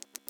Щелкает зубами песчаная мышь